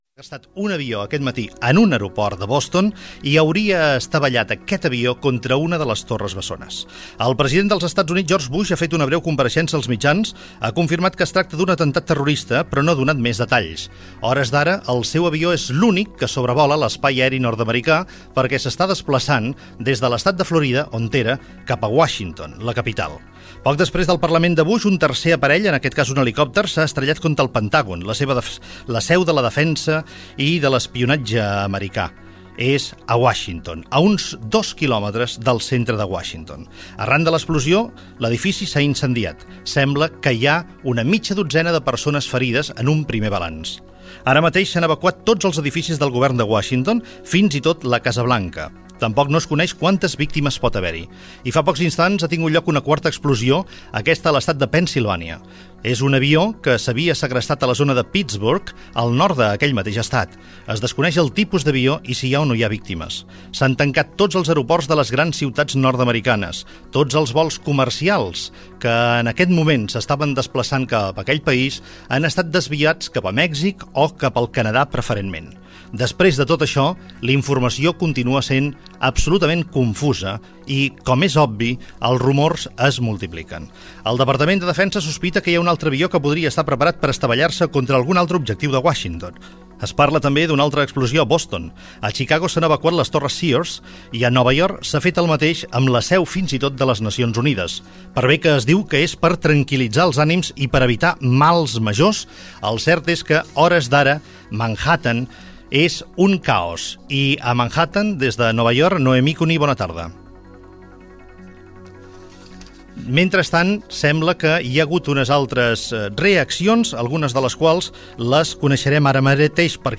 Connexió telefònica amb Madrid on s'ha establert un gabient de crisis.
Informatiu